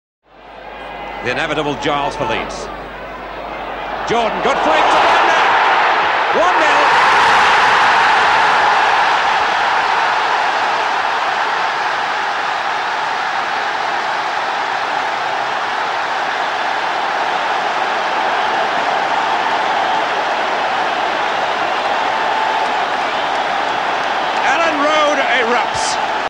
BBC Commentary, Leeds Utd v Barcelona 9.4.75
The din was deafening, like nothing I’d ever heard before, and rarely since. “Elland Road erupts” intoned David Coleman for the BBC, when he could make himself heard.